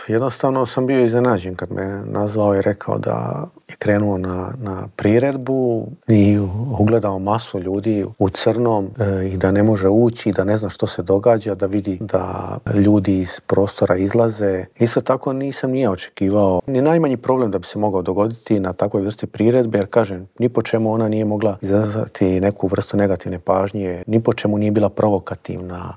Predsjednik Srpskog narodnog vijeća Boris Milošević u Intervjuu tjedna Media servisa poručuje da je teško objasniti kako je iz jedne male kulturne priredbe ovo preraslo u tako veliki problem.